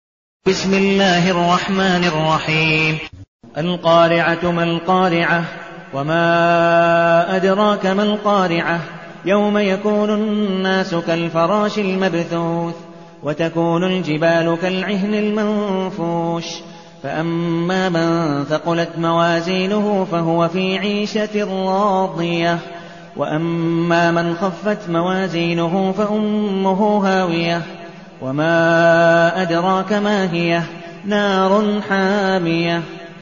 المكان: المسجد النبوي الشيخ: عبدالودود بن مقبول حنيف عبدالودود بن مقبول حنيف القارعة